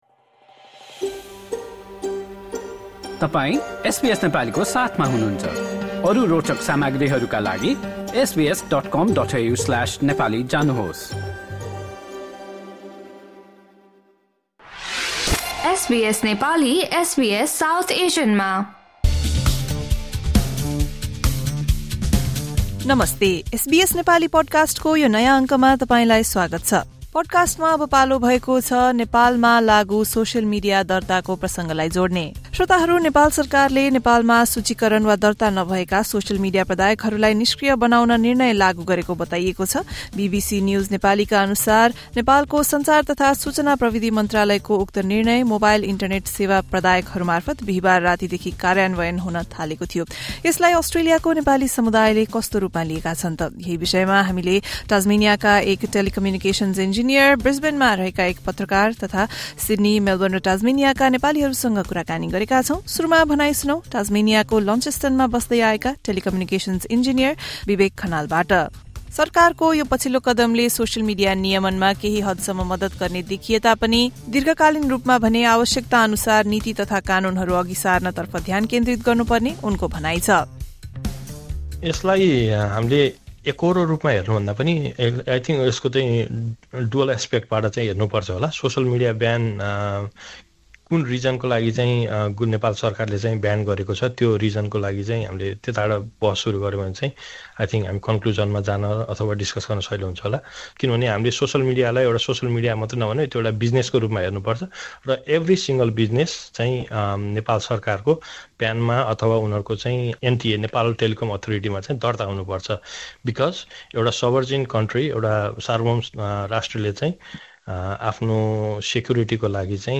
सिड्नी, मेलबर्न र टास्मेनियाका केही नेपालीहरूसँग एसबीएस नेपाली गरेको कुराकानी सुन्नुहोस्।